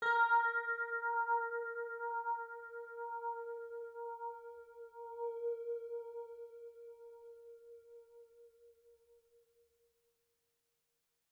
标签： ASharp5 MIDI音符-82 Korg的-Z 1 合成器 单票据 多重采样
声道立体声